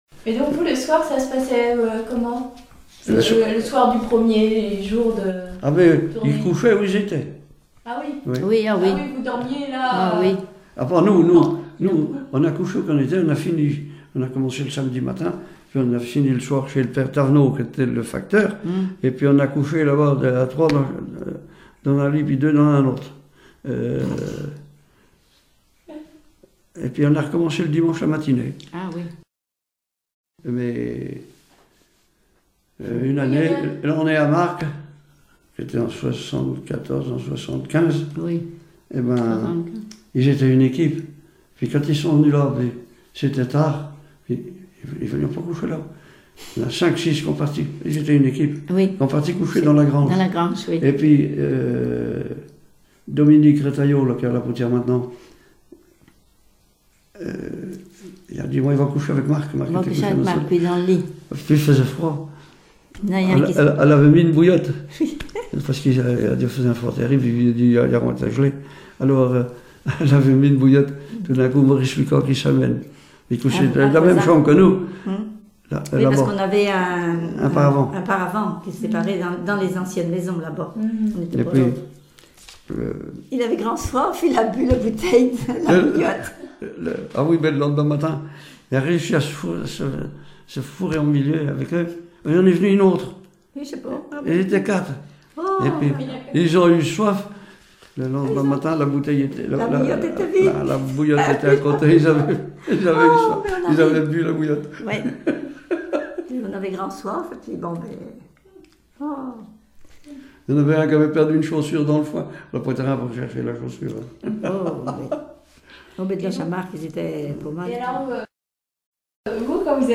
Témoignages sur les conscrits et chansons
Catégorie Témoignage